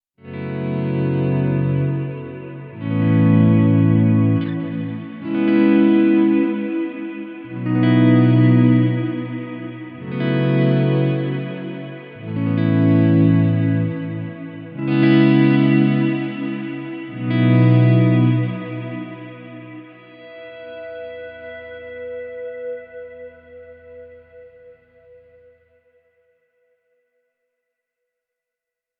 Sofort fällt mir der angenehme Widerstand des Pedals auf, mit dem gefühlvolle Volume-Swells sehr gut ausführbar sind.
Lehle Dual Expression Test Klangbeispiele
Volume-Swells mit dem Lehle Dual Expression